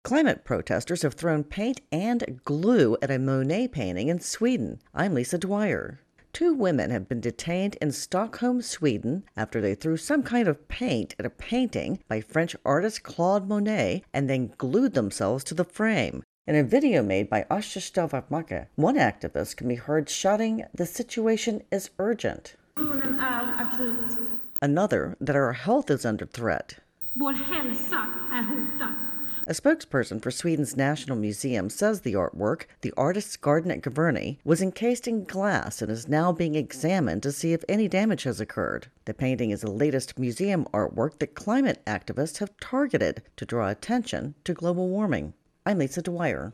((Sound is courtesy ATERSTALL VATMARKER , the courtesy is included in the wrap))